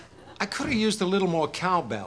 cowbell2.wav